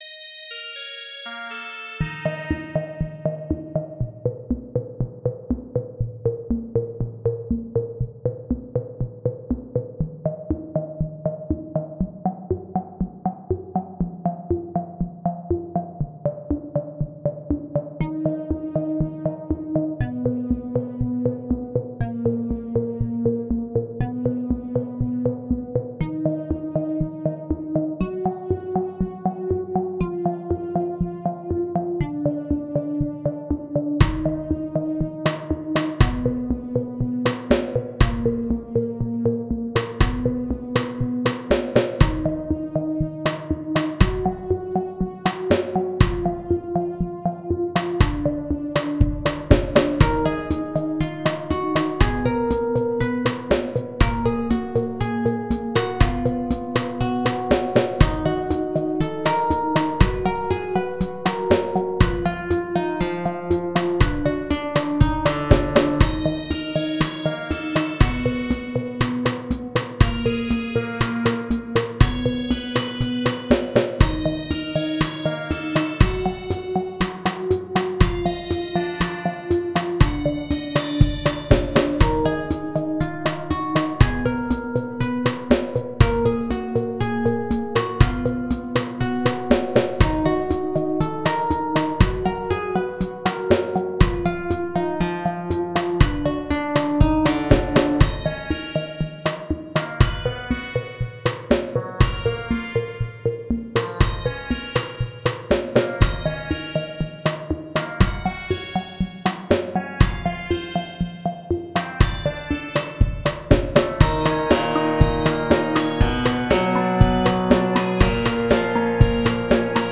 All the drums have been performed by Hydrogen.